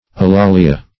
alalia \a*la"li*a\ ([.a]*l[=a]"l[i^]*[.a]), n. [NL., fr. Gr.